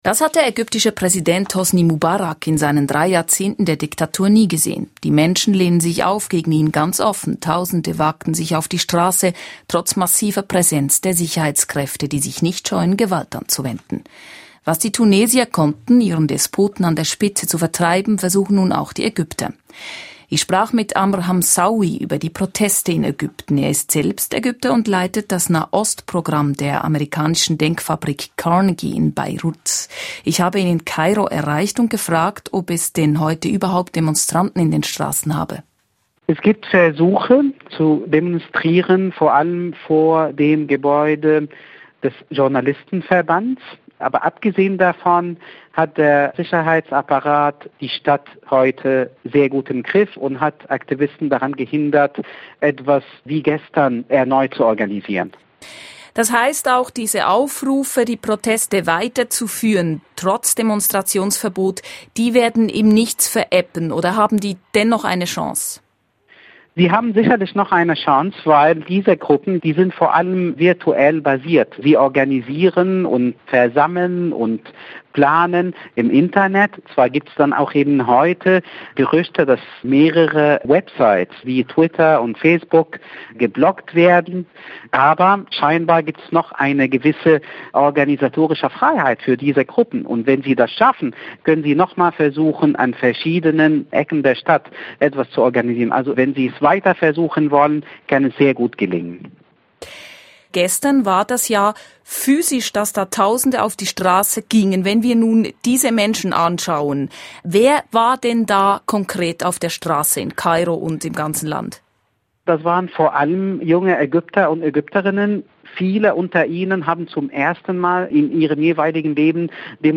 Doch habt die «neue» Protestgeneration gegen den Sicherheitsapparat eine Chance? Gespräch mit Amr Hamzawy.